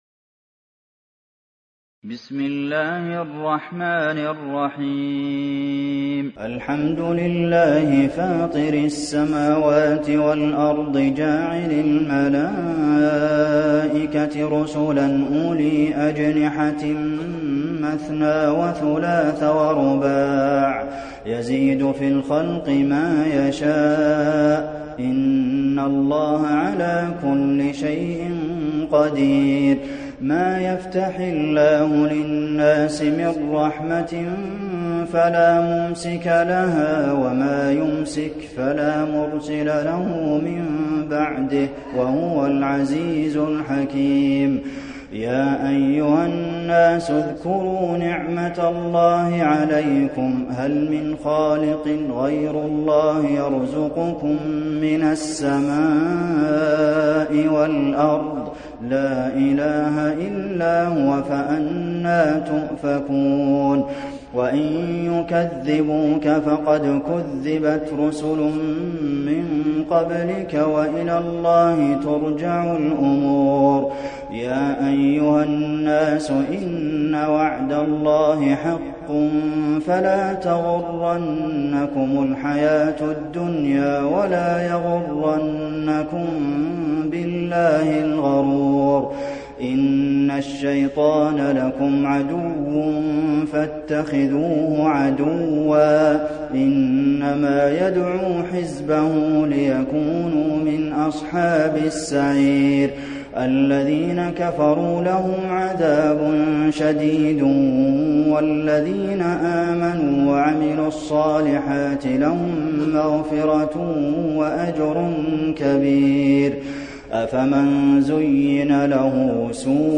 المكان: المسجد النبوي فاطر